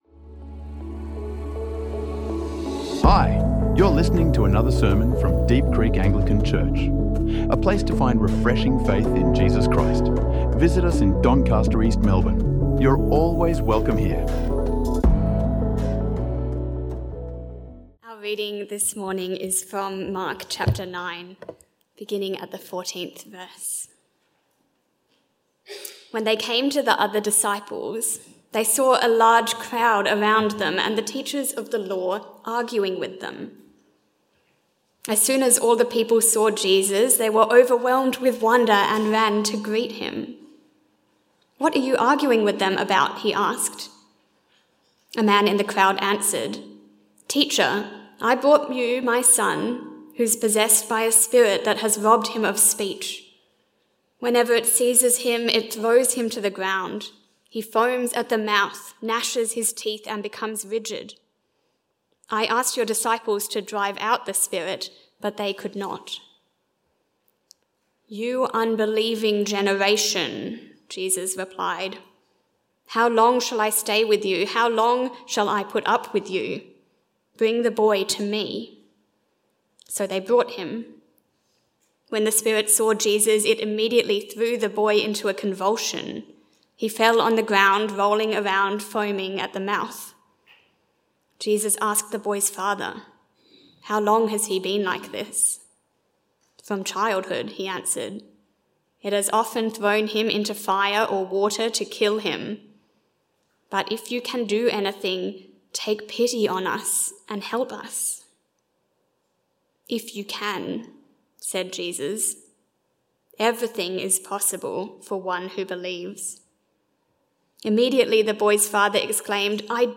This sermon explores what it truly means to have faith, especially when faced with everyday realities and doubt.